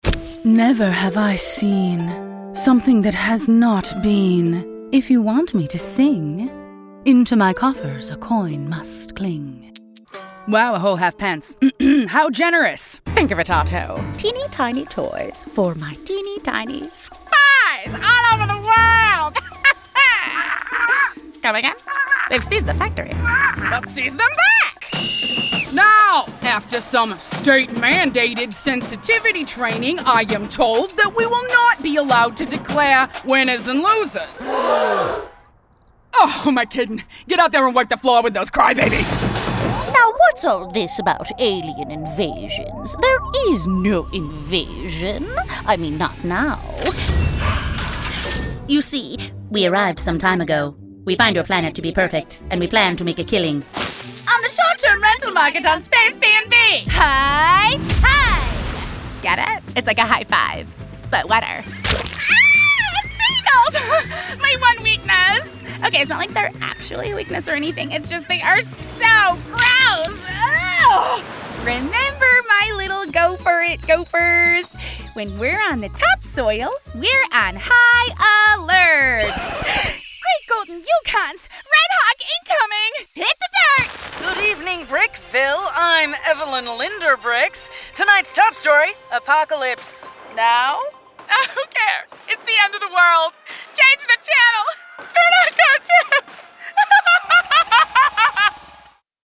ANIMATION VOICEOVER DEMOS
A world-class, commercial grade studio in North Hollywood, CA is where all my recording takes place.